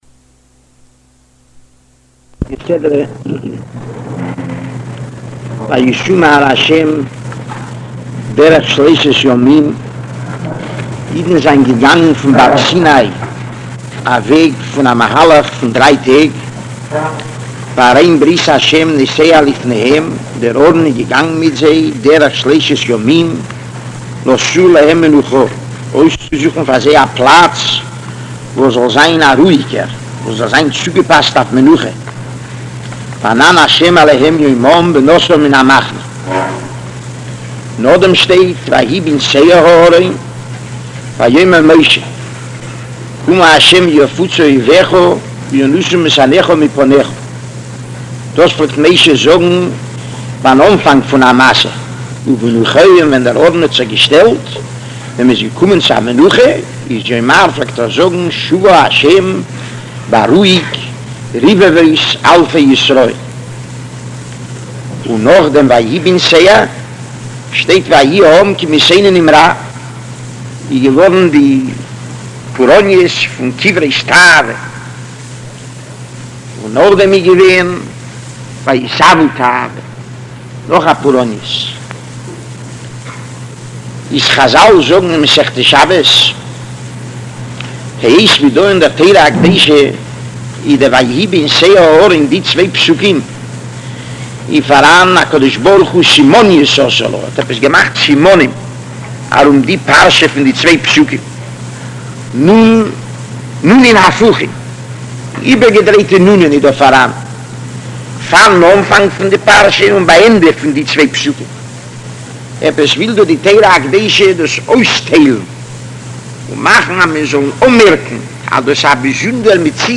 Parshas Behaalosicha Shiur Daas